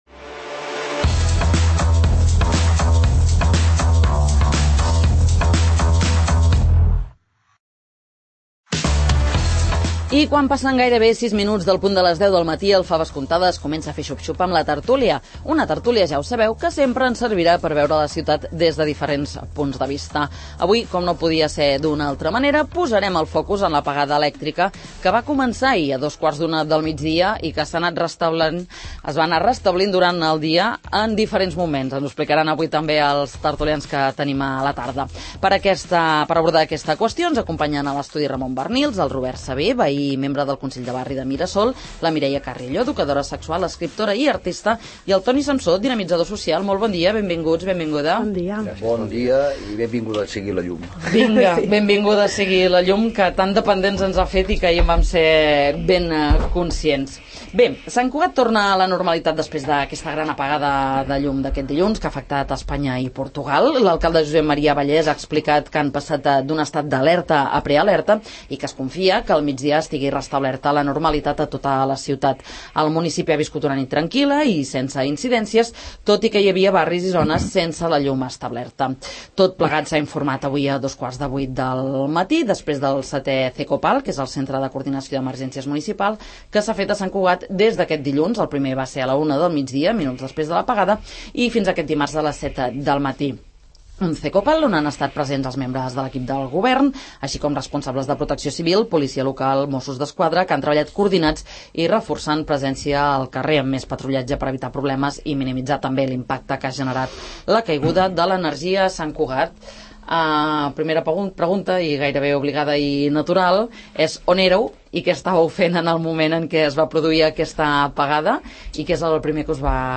Com ha viscut Sant Cugat l'apagada el�ctrica? En parlem a la tert�lia del 'Faves comptades'